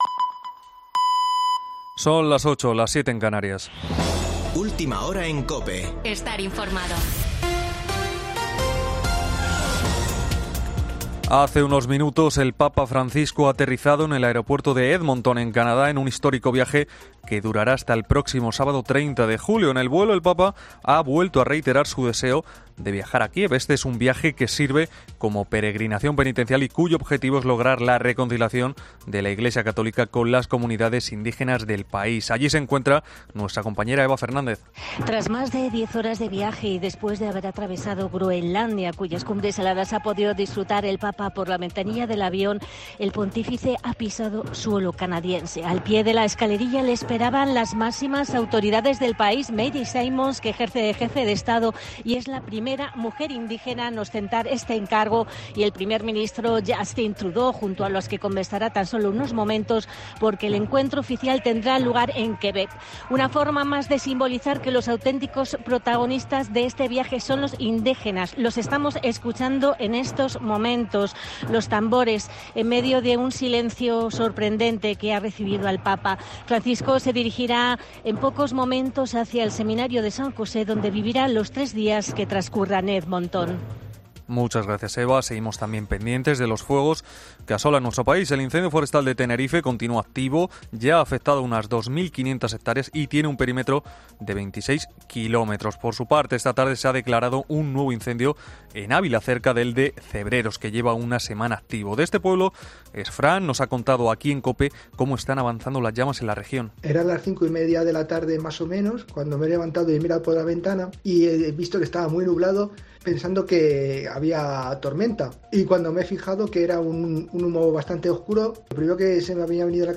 Boletín de noticias de COPE del 24 de julio de 2022 a las 20:00 horas